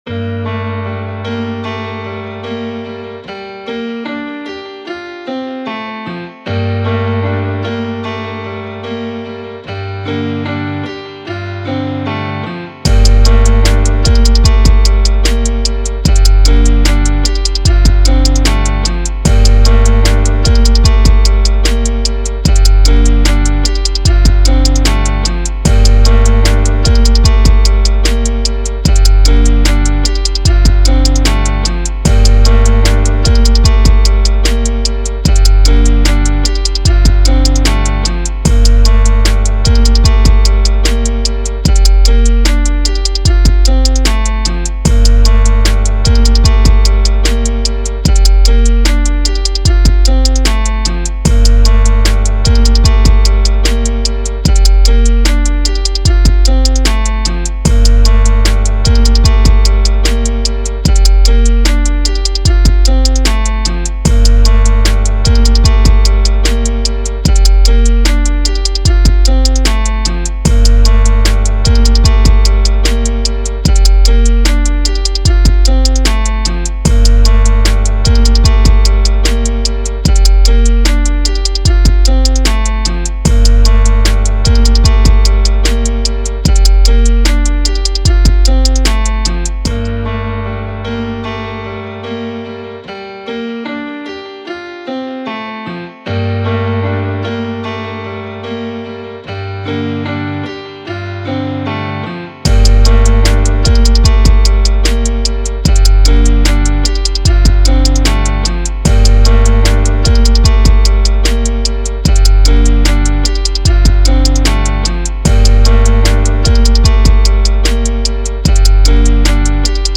Drill and Club type beat